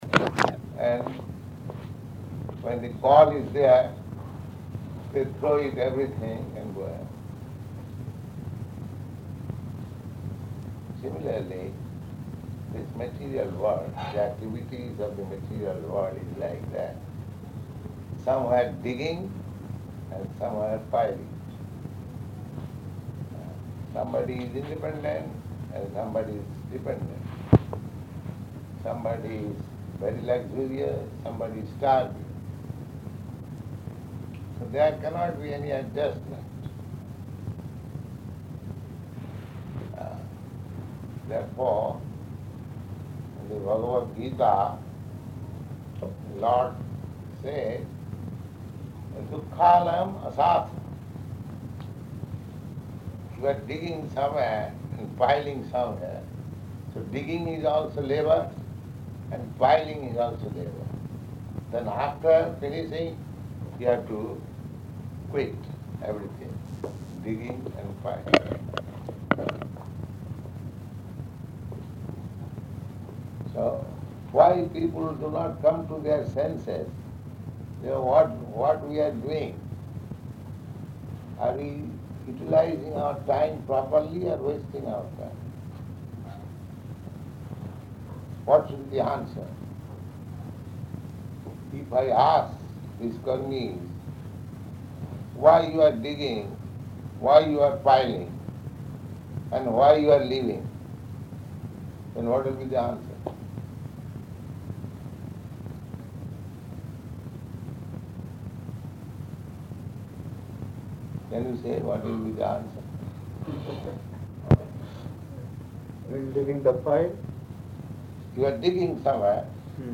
Room Conversation
Room Conversation --:-- --:-- Type: Conversation Dated: September 24th 1969 Location: London Audio file: 690924R1-LONDON.mp3 Prabhupāda: ...and when the call is there, they throw it, everything, and go away.
Guest (1) [Indian man]: When digging the pile?